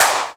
VEC3 Claps 063.wav